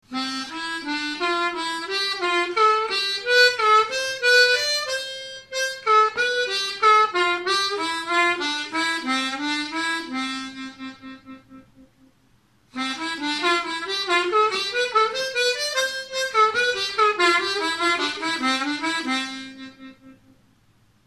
Con este ejercicio aprendemos a controlar los varios grados de bending en el agujero 2 aspirado.
Tonalidad de la armónica: C
AHCOD - Audio_Hole 2 draw bending routine phrase 4.MP3